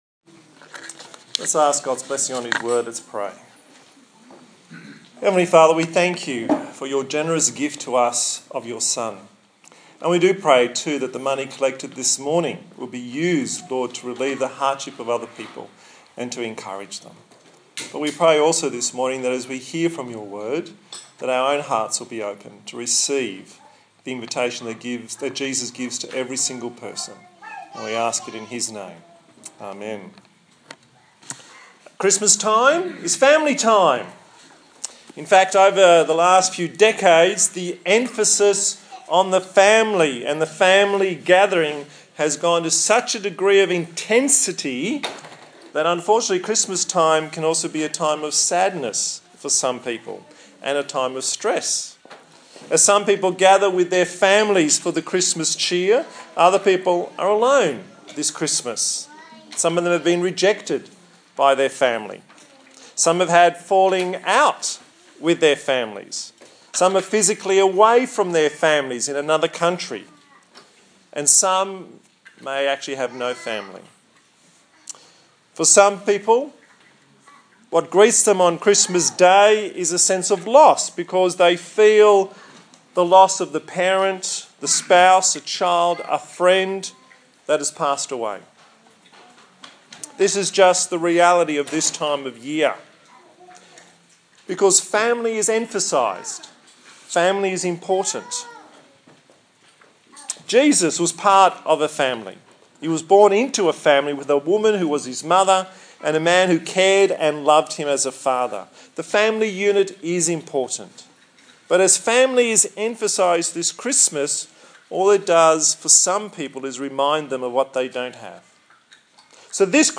1 John 1:1-4 Service Type: Sunday Morning God comes to earth to live with us.